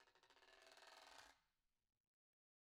Guiro-Slow_v1_Sum.wav